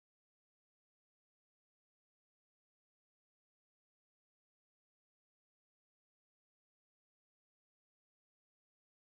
So I made a blank sms tone a while ago, it's a tone of silence in mp3 form. You can assign it as your messaging tone so you will still get them, phone will ring but no sound will come through on messages.